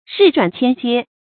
日转千街 rì zhuǎn qiān jiē 成语解释 指乞丐沿街行乞。
ㄖㄧˋ ㄓㄨㄢˇ ㄑㄧㄢ ㄐㄧㄝ